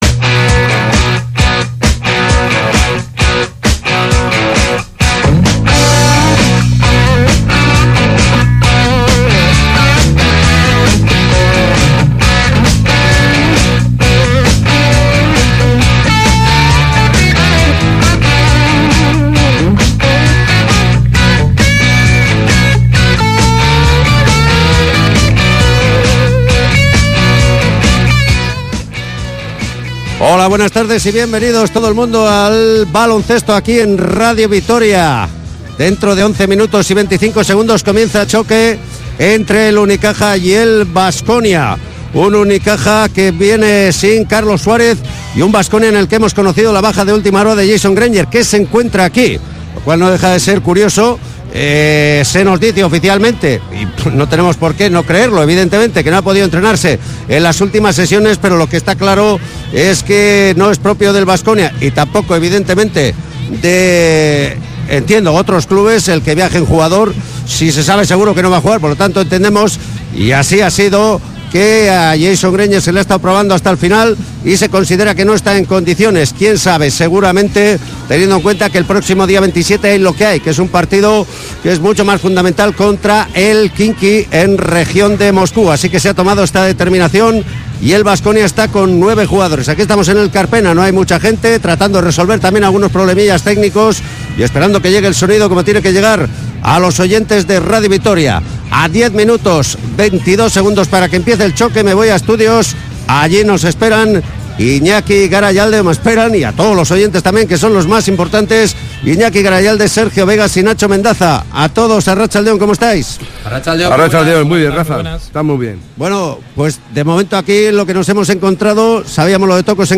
Unicaja-baskonia jornada 13 ACB 2018-19 retransmisión Radio Vitoria (solo primera parte)